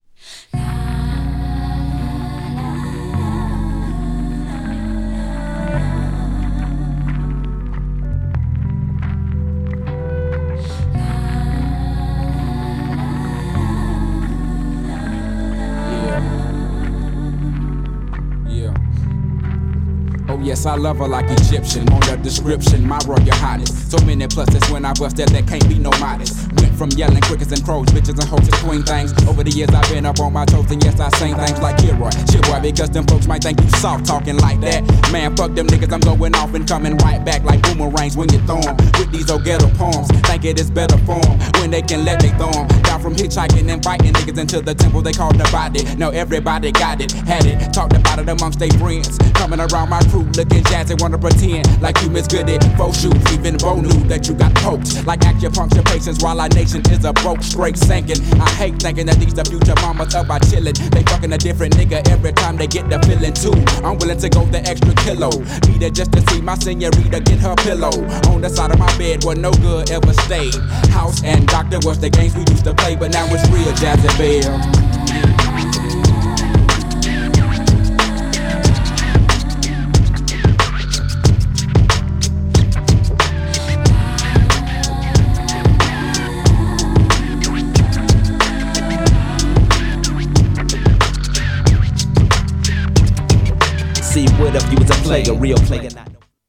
GENRE Hip Hop
BPM 91〜95BPM
2_MC'S
featに男性ボーカル
フックでR&Bなコーラスがイイ! # メロウHIPHOP # 渋い系HOPHOP # 独特のグルーブ感